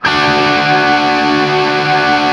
TRIAD D  L-L.wav